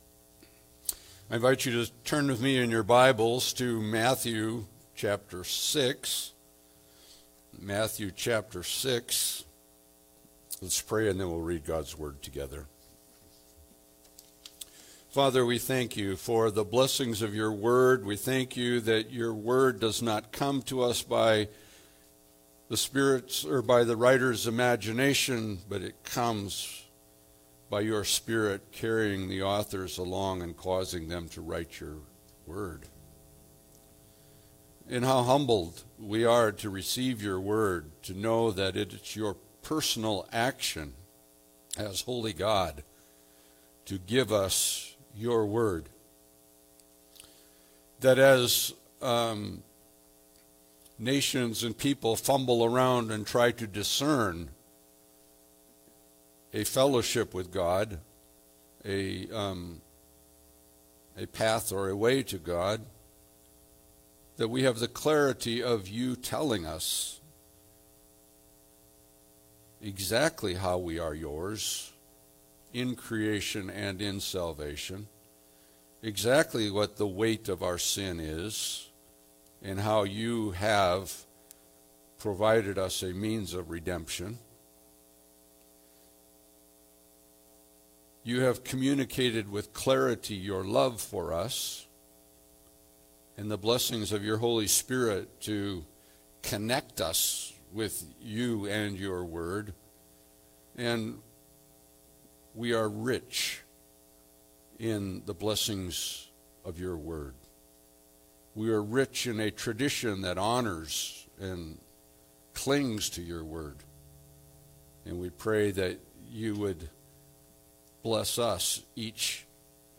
Passage: Matthew 6 Service Type: Sunday Service